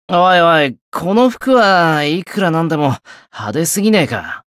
觉醒语音 喂喂，这件衣服怎么看都太花俏了吧？